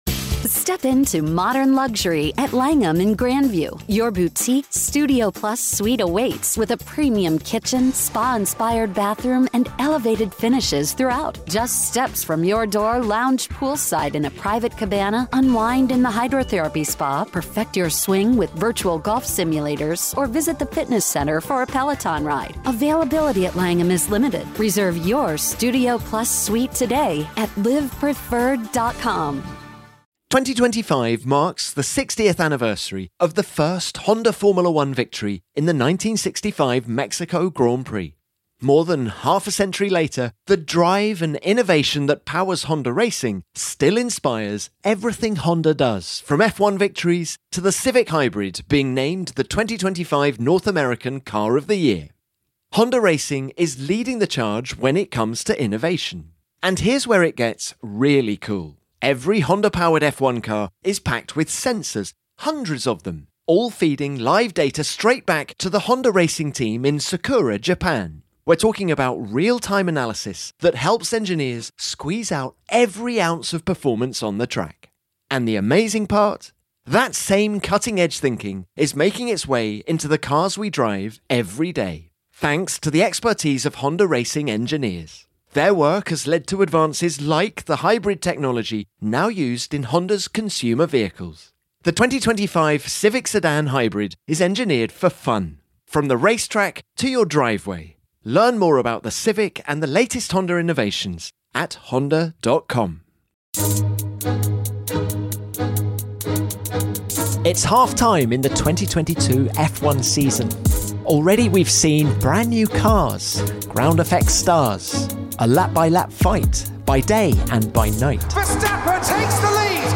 Former Ferrari race-winner Gerhard Berger joins the team to give his take on Max Verstappen vs Charles Leclerc, pick the star performers of the season so far and to look ahead to the 2022 French Grand Prix.